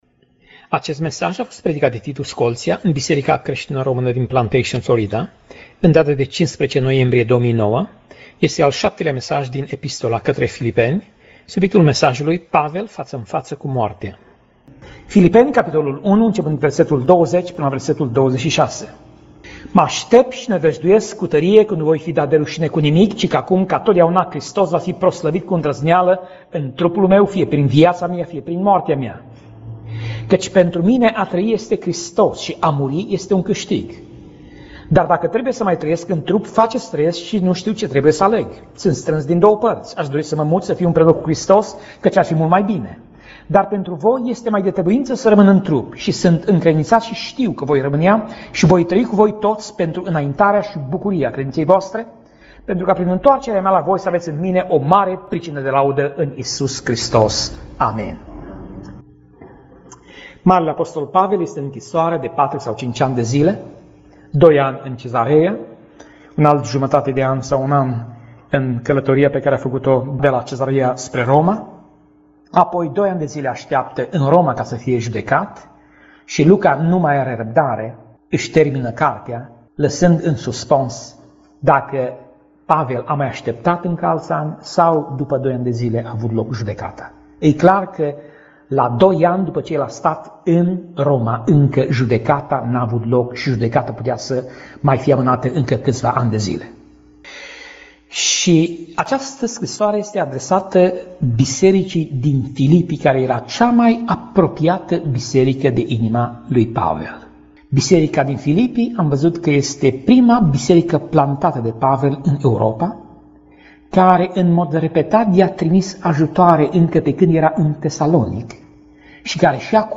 Pasaj Biblie: Filipeni 1:12 - Filipeni 1:26 Tip Mesaj: Predica